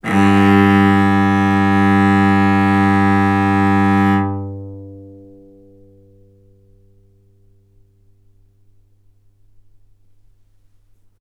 vc-G2-ff.AIF